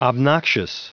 Prononciation du mot obnoxious en anglais (fichier audio)
Prononciation du mot : obnoxious